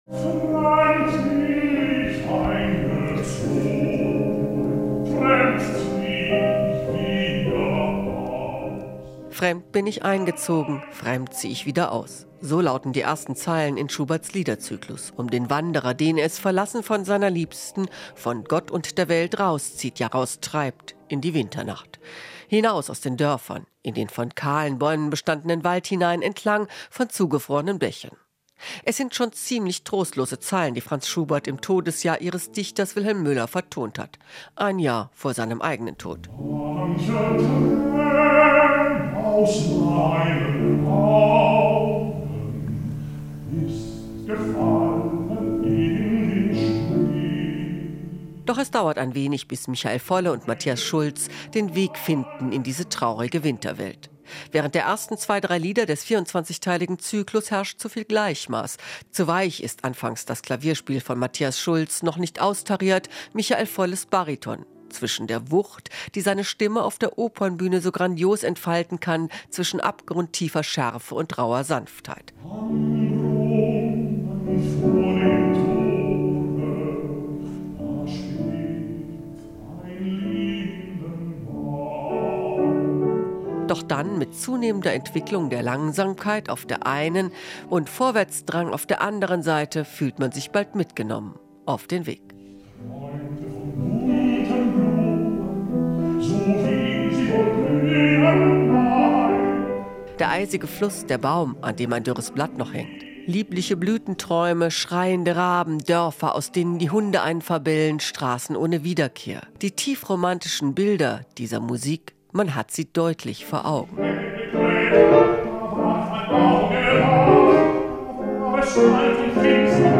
Der romantische Liedzyklus "Die Winterreise" von Franz Schubert war am Montagabend im Apollosaal zu hören. Gesungen wurde er von Bariton Michael Volle – und der machte den Konzertabend zu einem echten Erlebnis.
Kultur - Der einsame Wanderer: "Die Winterreise" mit Michael Volle